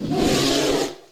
PixelPerfectionCE/assets/minecraft/sounds/mob/polarbear/hurt3.ogg at mc116